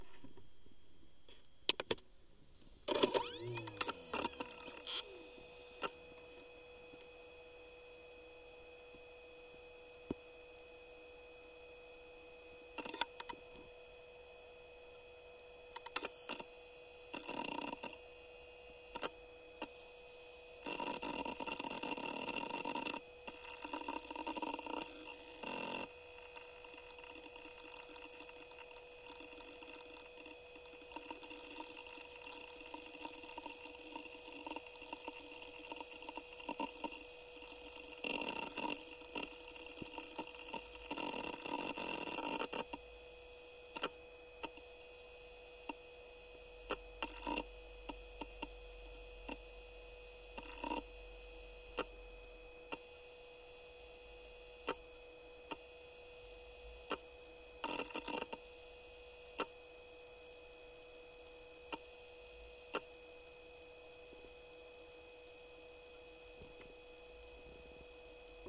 描述：割草机的马达/马达 de un cortacespedesped
Tag: 现场录音